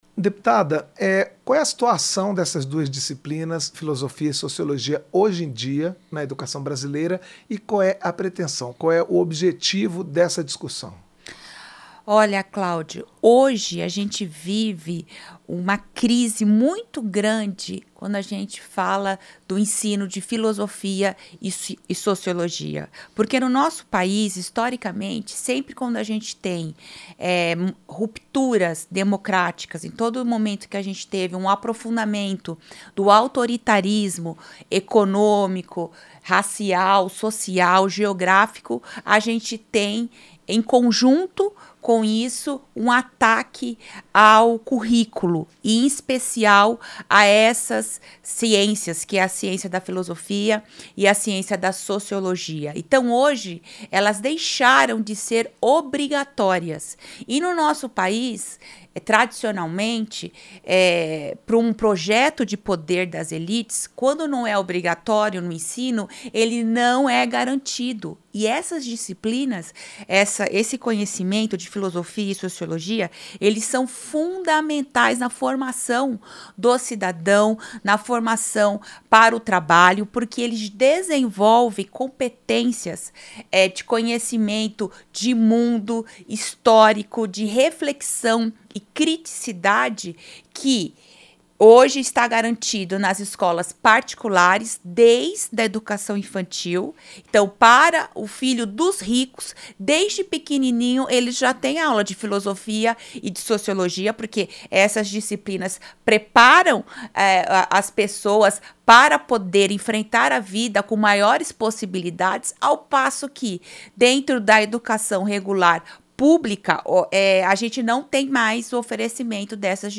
Entrevista - Dep. Professora Luciene Cavalcante (Psol-SP)